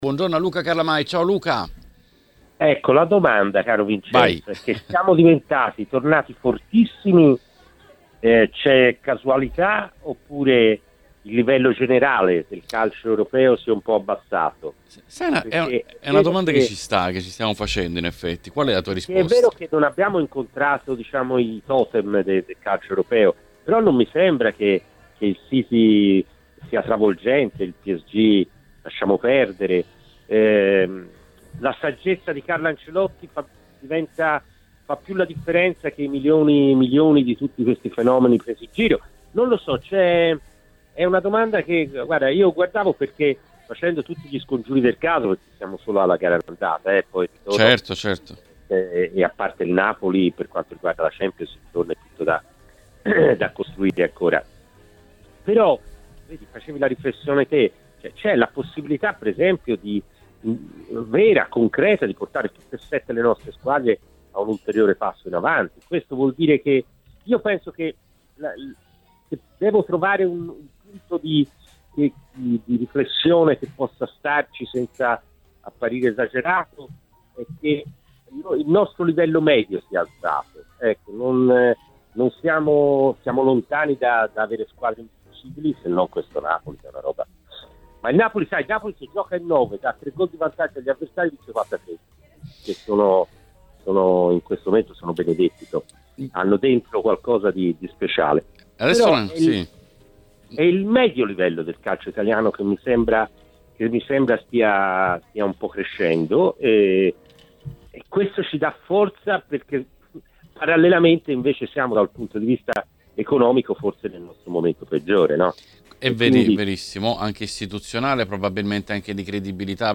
Durante l'appuntamento con l'Editoriale , sulle frequenze di TMW Radio è intervenuto